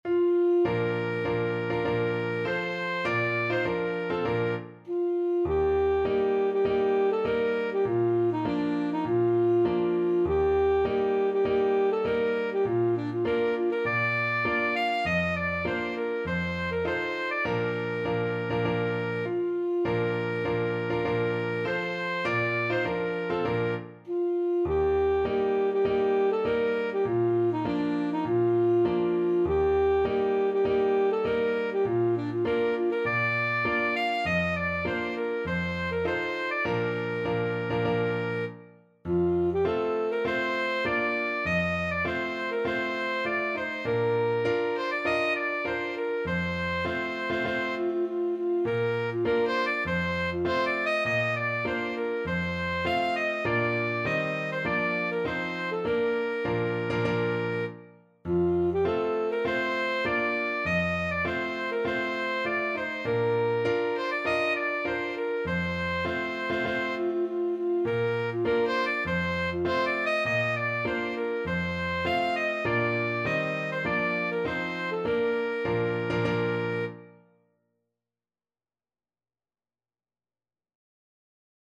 Alto Saxophone
4/4 (View more 4/4 Music)
Bb major (Sounding Pitch) G major (Alto Saxophone in Eb) (View more Bb major Music for Saxophone )
Classical (View more Classical Saxophone Music)
hohenfriedberger_march_ASAX.mp3